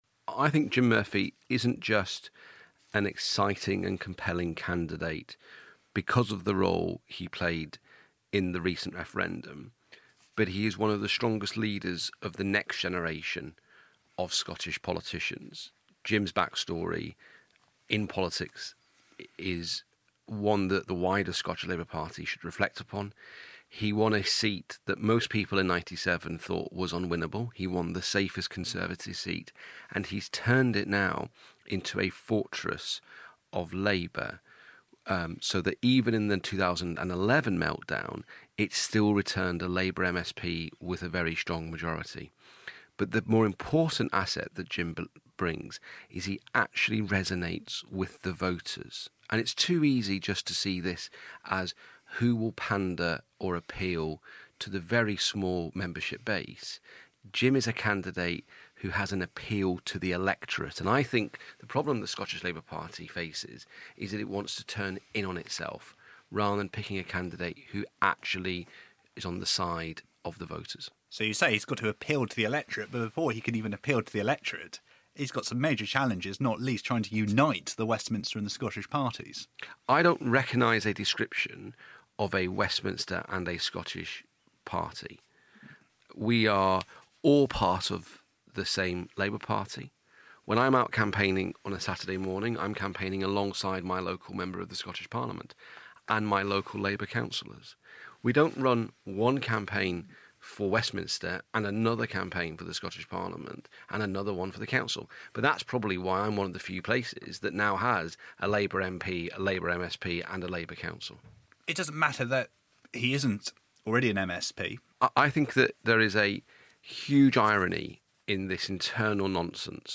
My interview with Thomas Docherty - Shadow Deputy Leader of the House - on the Scottish Labour leadership. Recorded for Radio 4's World At One for 30 October